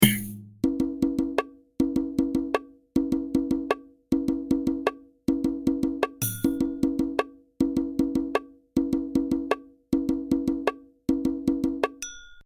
44 grouped in 3 (4 bar loop) 1st displacement